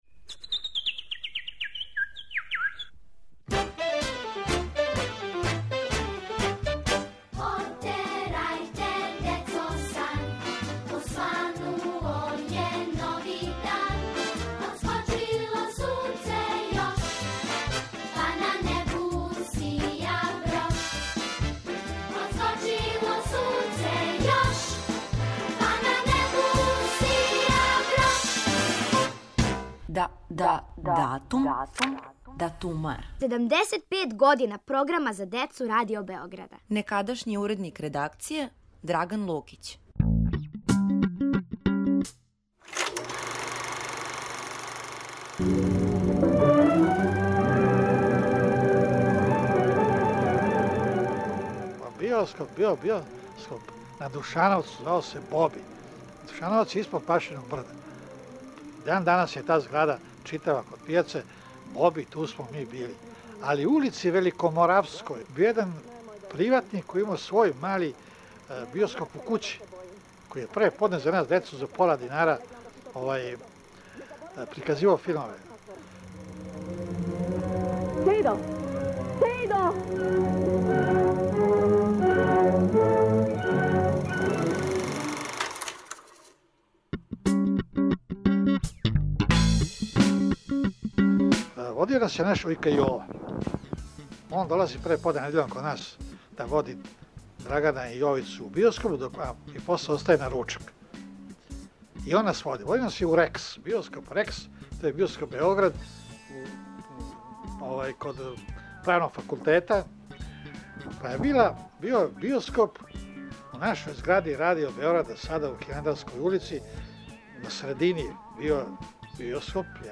Поводом 75. рођендана Програма за децу и младе, слушате гласове некадашњих уредника редакције. Овог месеца то је Драган Лукић.